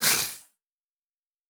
Monster_04_Attack.wav